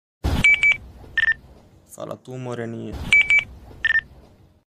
Categoria Alarmes